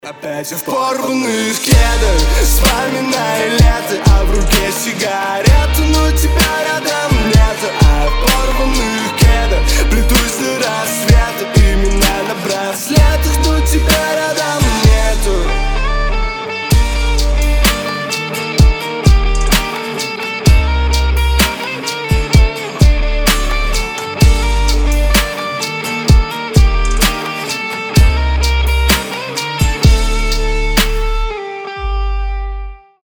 • Качество: 320, Stereo
гитара
лирика
грустные